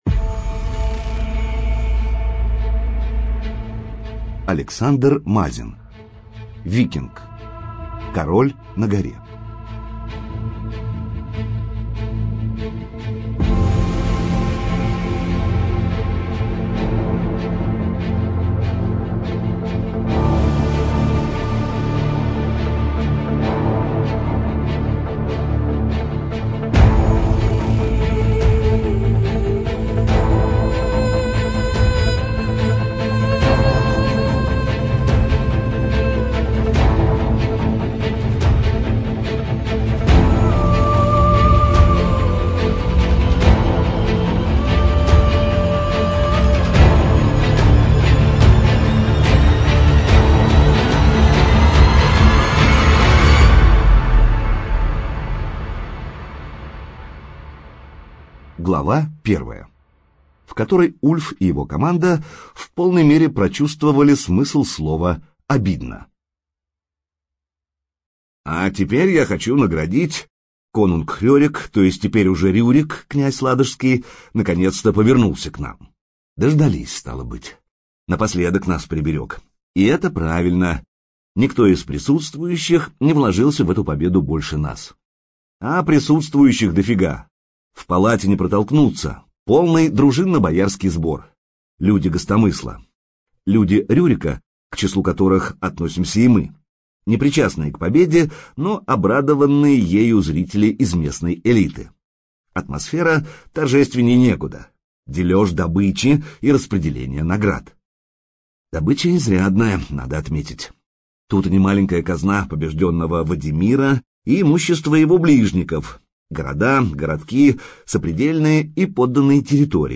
ЖанрФантастика, Альтернативная история